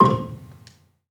Gambang-C2-f.wav